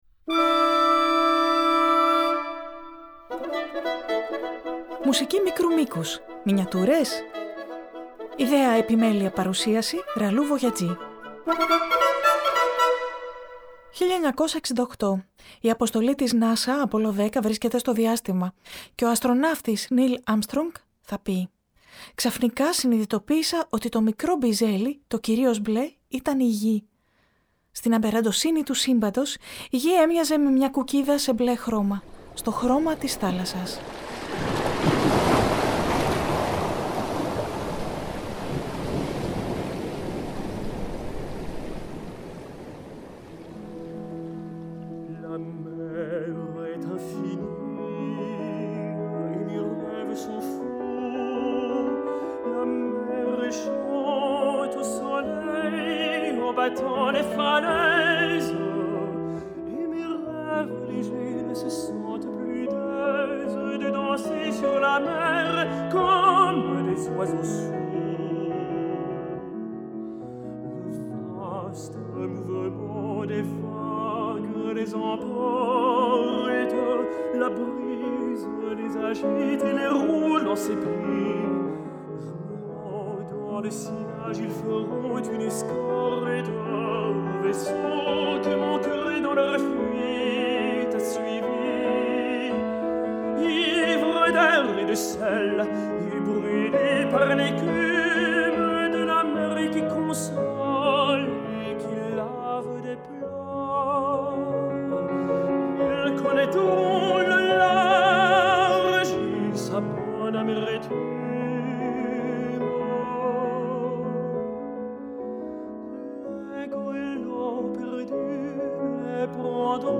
Η ‘’Μουσική μικρού μήκους-Μινιατούρες;‘’ στις καθημερινές μικροσκοπικές και εβδομαδιαίες θεματικές εκπομπές προσκαλεί σε περιηγήσεις σε μια φανταστική έκθεση- σε μια ‘’ιδιωτική συλλογή’’ -μουσικών έργων ‘’μικρού μήκους’’ από όλες τις εποχές και τα είδη της αποκαλούμενης δυτικής ευρωπαϊκής λόγιας και συγγενών ειδών: